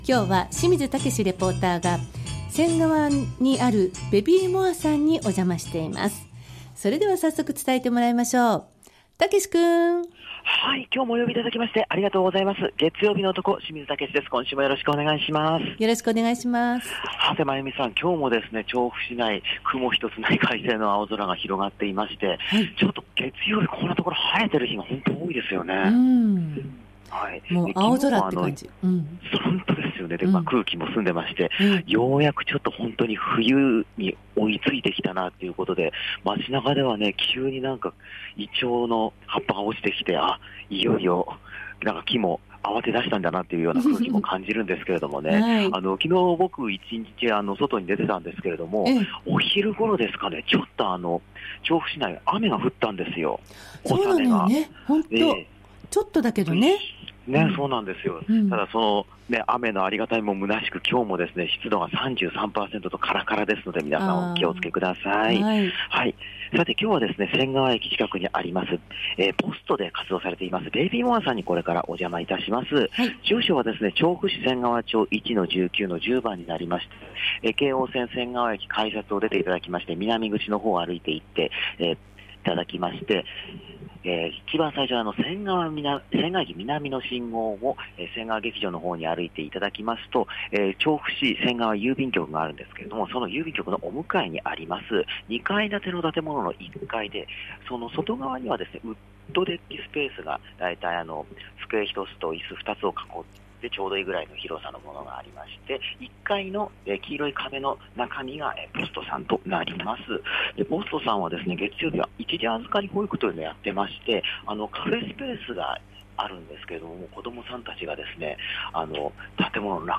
午後のカフェテラス 街角レポート
冬空の下からお届けした、本日の街角レポートは仙川POSTOで活動されている『baby more』さんの